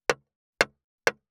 464,切る,包丁,厨房,台所,野菜切る,
効果音厨房/台所/レストラン/kitchen食材